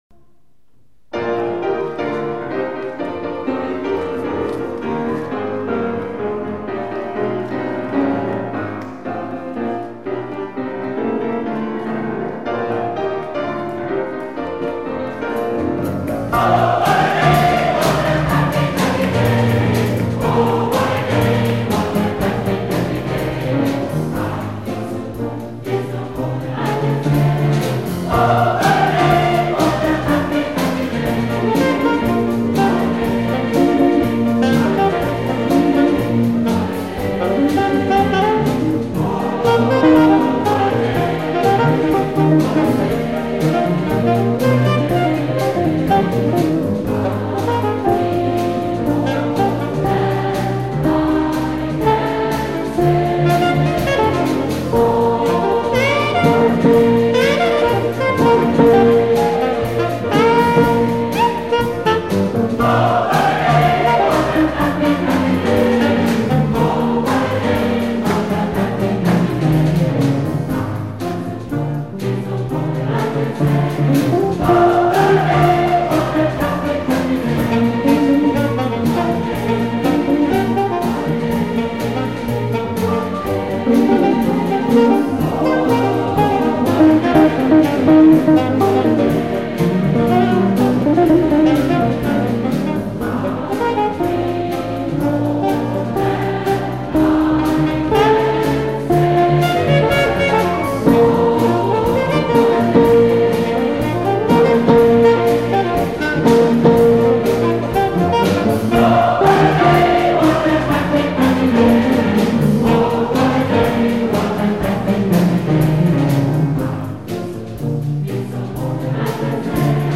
FREDSKONSERT i S:ta Helena kyrka okt 1984.
Nu skulle kören DISSonans, där jag var nybliven medlem, få sjunga med honom och de andra superbegåvningarna i bandet, Bengt Hallberg piano, Rune Gustafsson gitarr, Georg Riedel bas.
Inspelningen är från konserten i kyrkan, inte det bästa ljudet men de bästa musikerna.
Och att vara med i en stor kör!